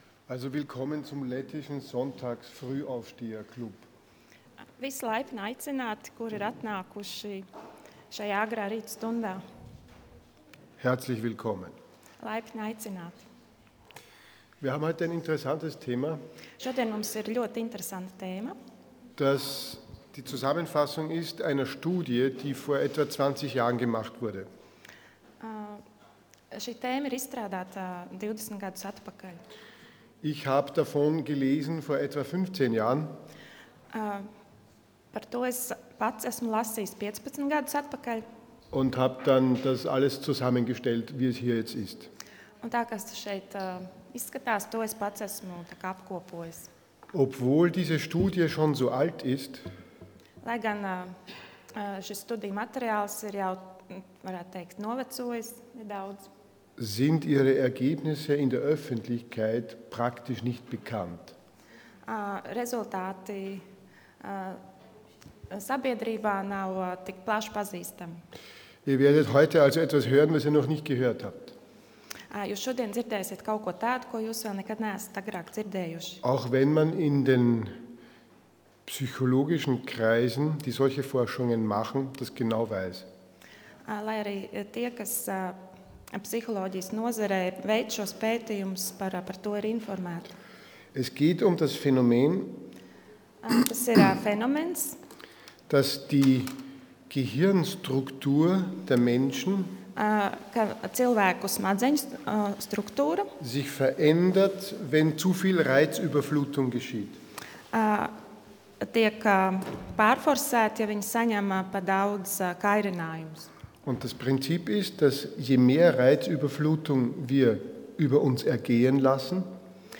Seminārs - Bērnu audzināšana